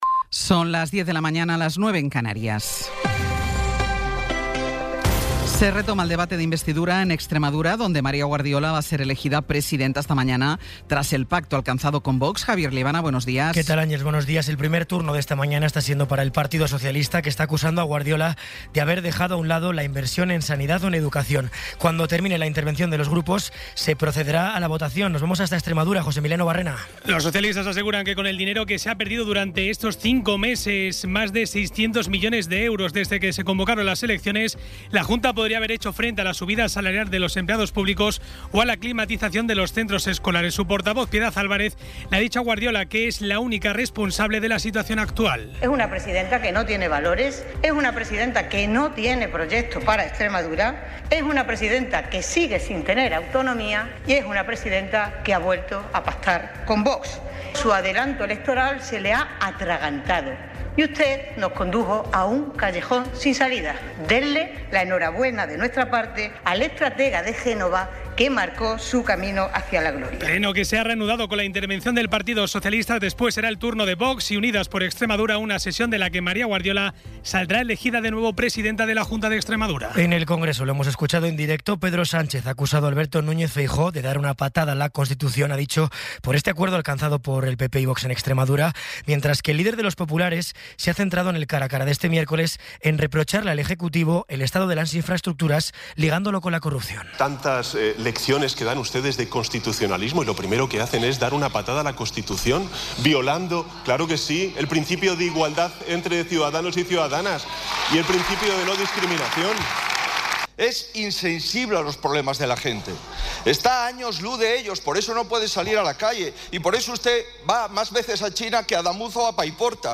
Resumen informativo con las noticias más destacadas del 22 de abril de 2026 a las diez de la mañana.